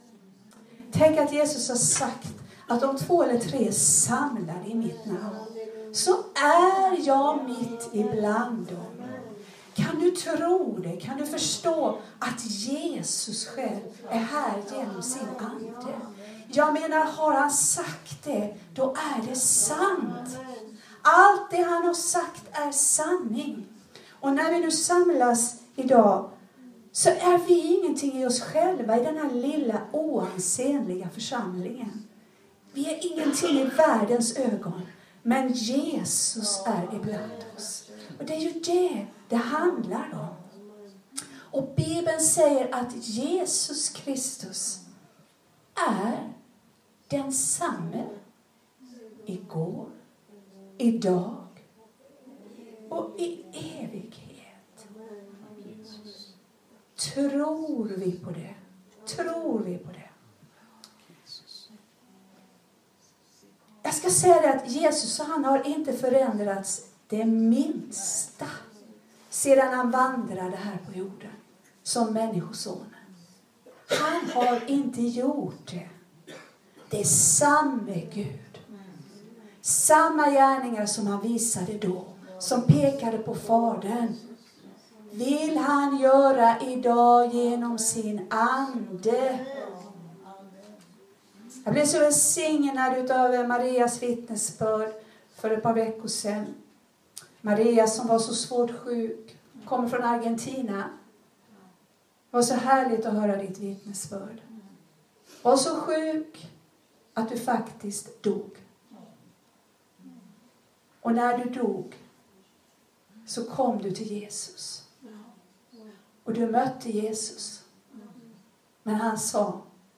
Här nedan kan du lyssna på och ladda ned (högerklicka på länkade texter) de predikningar som hållits i församlingen.